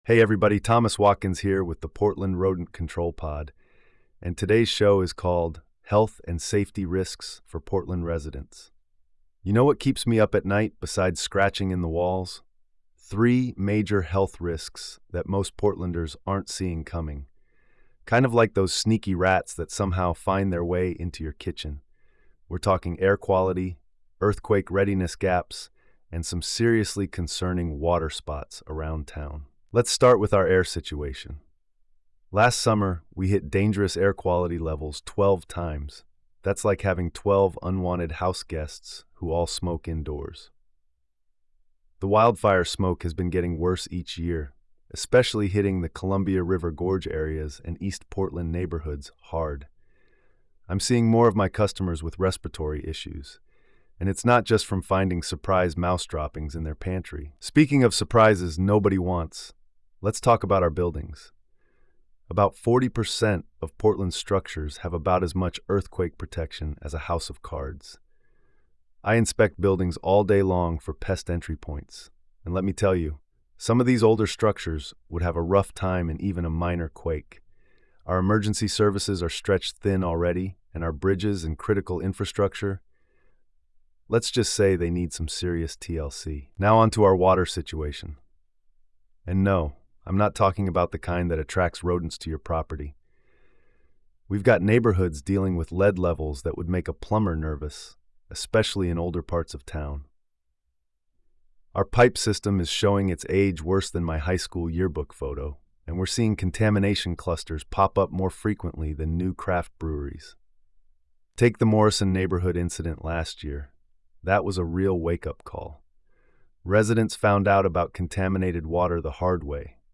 pest control expert